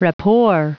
Prononciation du mot rapport en anglais (fichier audio)
Prononciation du mot : rapport